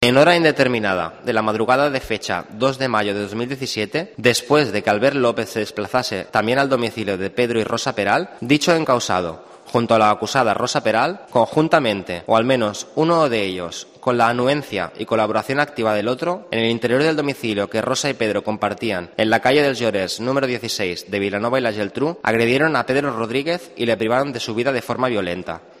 Audio del jurado popular por el crimen de la Guardia Urbana